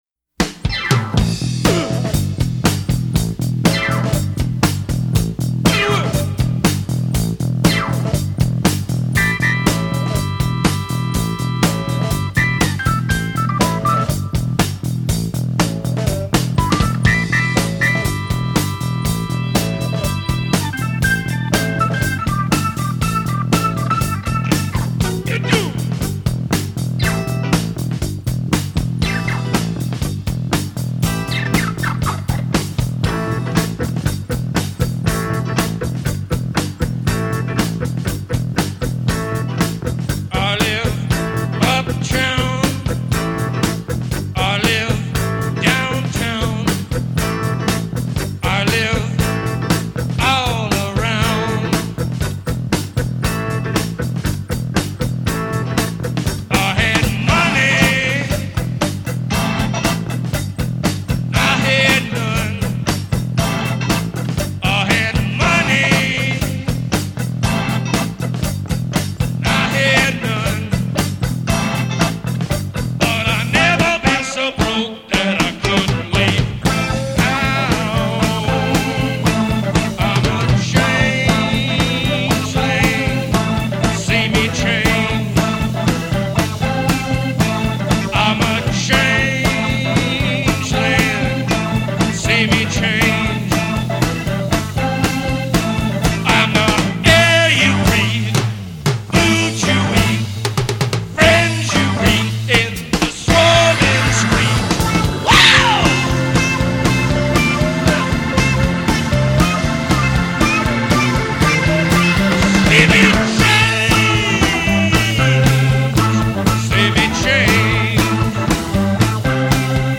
Rock Рок Рок музыка